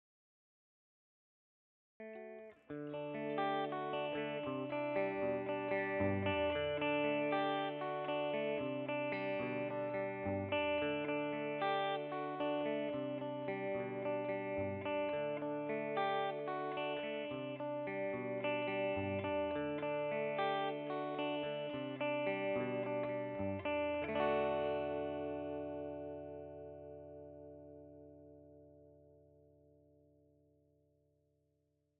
Demo Audio realizzate presso Trees Music Studio di Cava de’Tirreni
Chitarra Elettrica MD441 CLEAN – UAD Apollo x8
Chitarra Elettrica – Gibson Les Paul Standard
Amplificatore per Chitarra – Fender Deville
Microfono – Sennheiser MD441 U